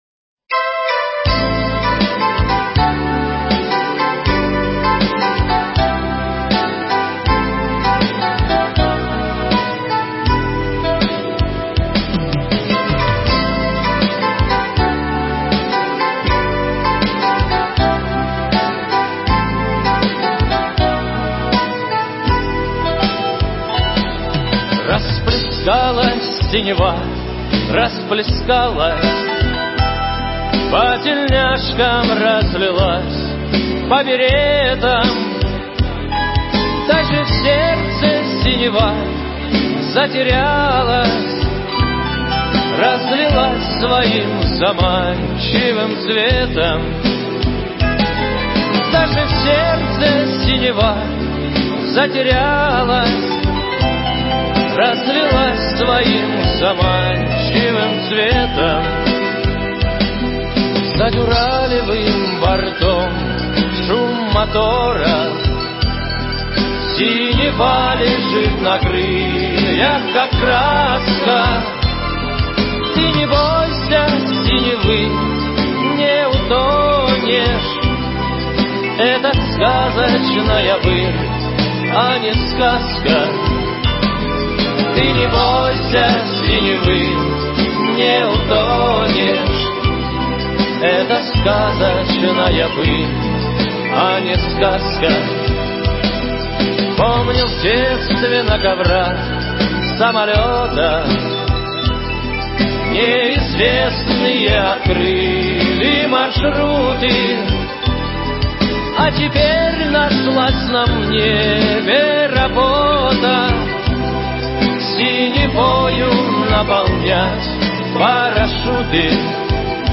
Поздравление с Днем воздушно-десантных войск России от мэра города Иркутска Руслана Болотова.